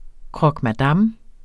Udtale [ kʁʌgmaˈdɑmː ]